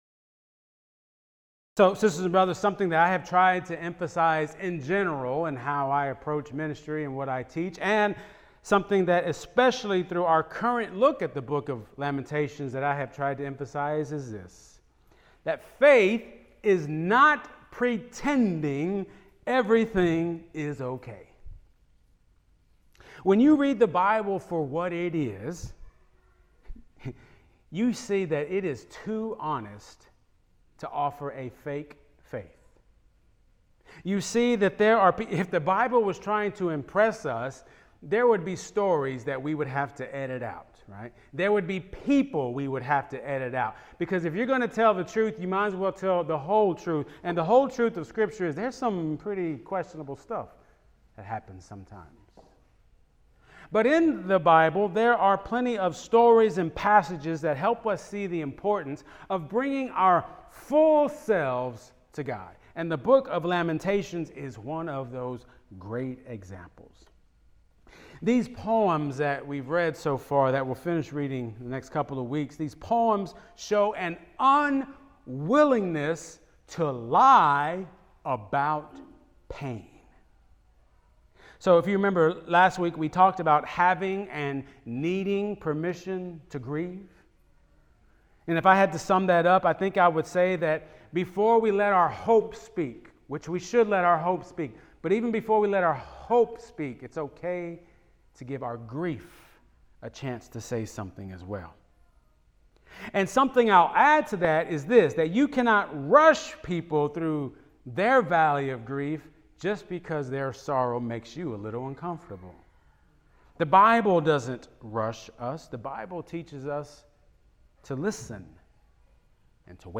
Opening Prayer